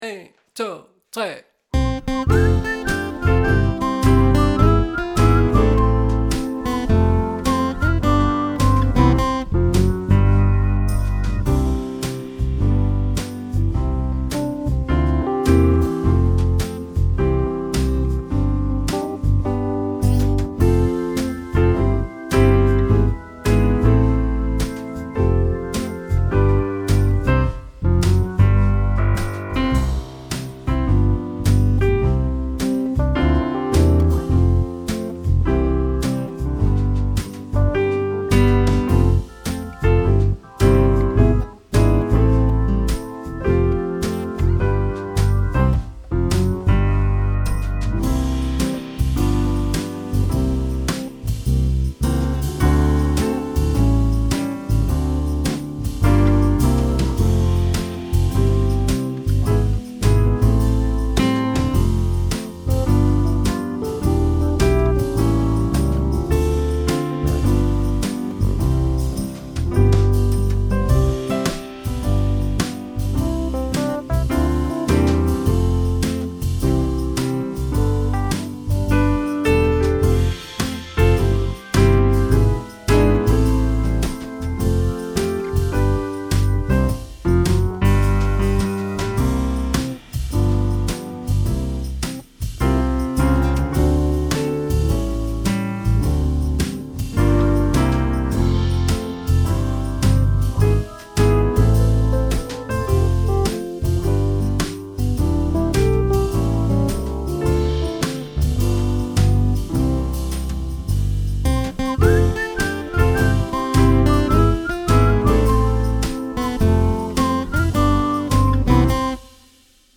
I år er der ingen video med fagter - men der er tilgæld en karaokeversion:
Ugens bibelvers - karaoke
Herren-selv-gaar-foran-dig-Karaoketrack.mp3